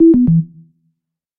Звуки подключения, отключения